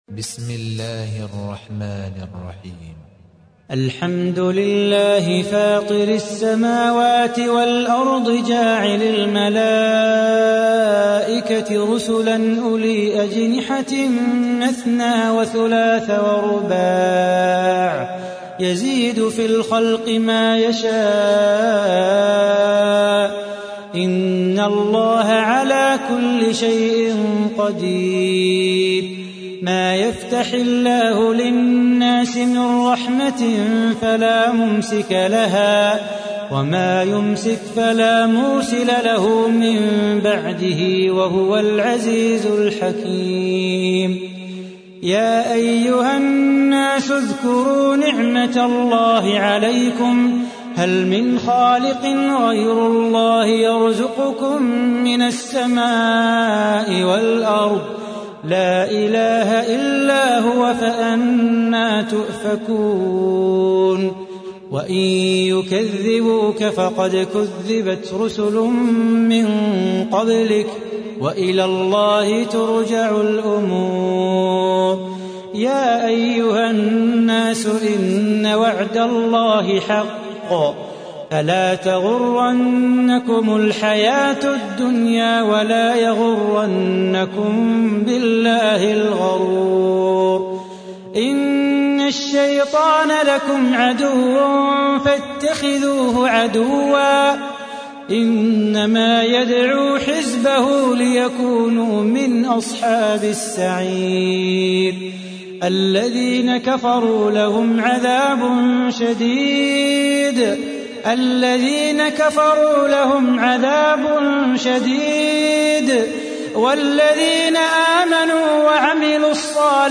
تحميل : 35. سورة فاطر / القارئ صلاح بو خاطر / القرآن الكريم / موقع يا حسين